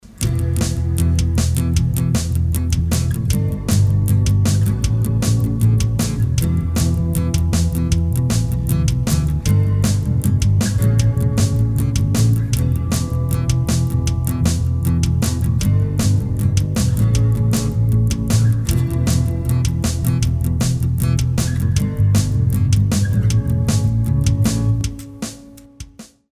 acousticmute.mp3